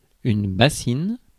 Ääntäminen
Ääntäminen France: IPA: [ba.sin] Haettu sana löytyi näillä lähdekielillä: ranska Käännös Ääninäyte Substantiivit 1. basin US 2. bowl US 3. vat US 4. tub 5. bowlful Suku: f .